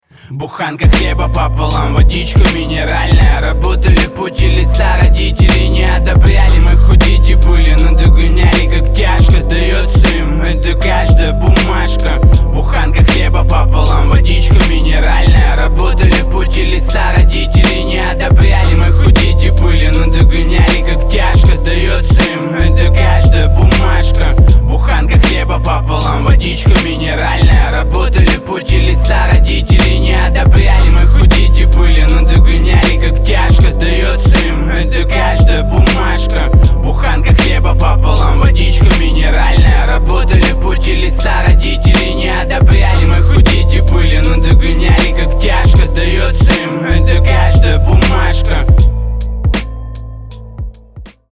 • Качество: 320, Stereo
мужской вокал
русский рэп